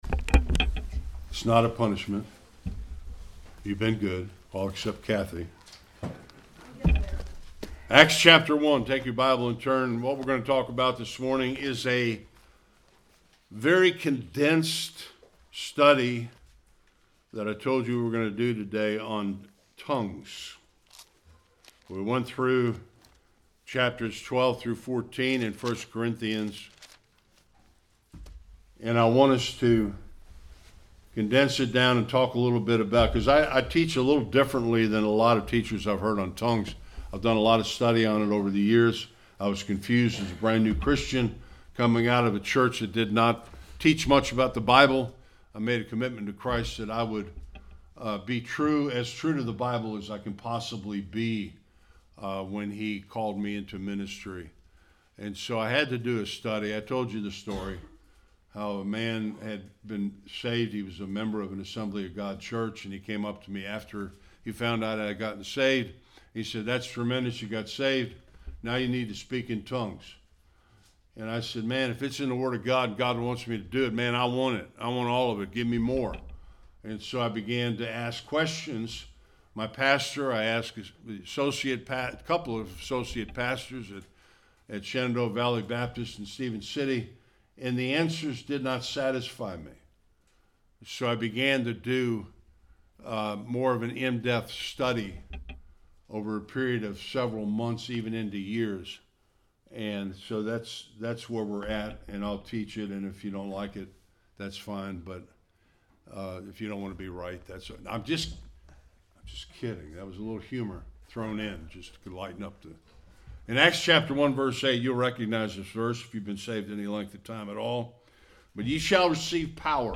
Acts 2 Service Type: Sunday Worship Were the tongues in Acts 2 Just languages.